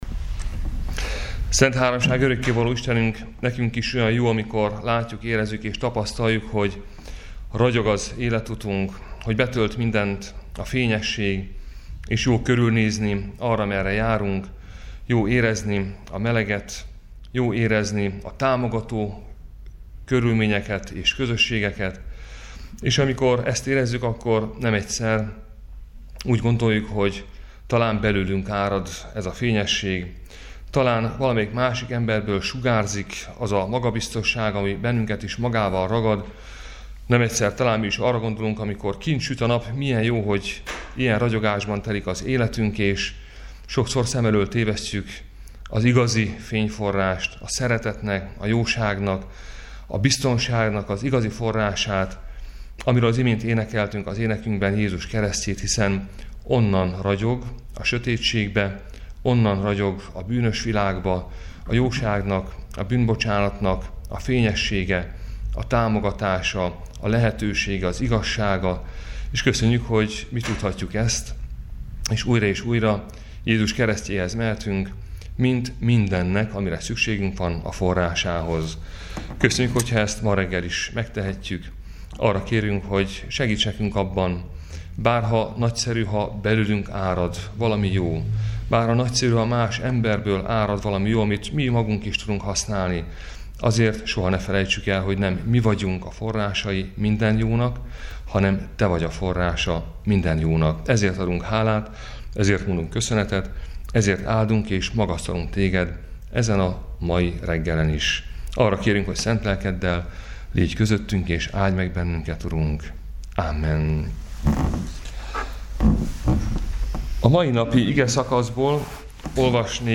Áhítat, 2019. május 8.
Áhítatok a püspöki hivatalban 2019. május 08.